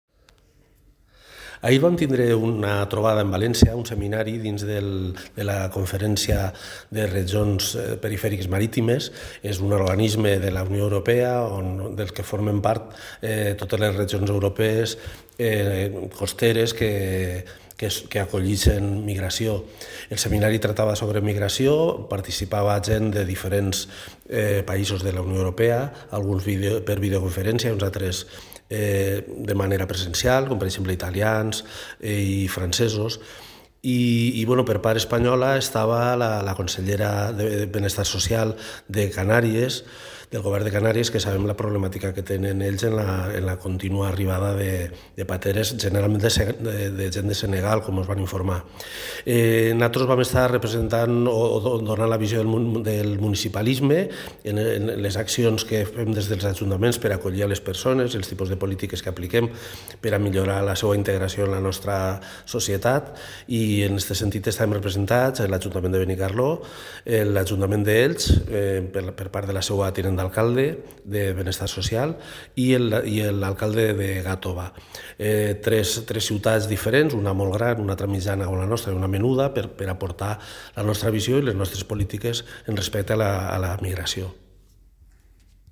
• València acull un seminari sobre polítiques migratòries que ha comptat amb una ponència de l’alcalde de Benicarló.
alcalde_seminari_migracions.mp3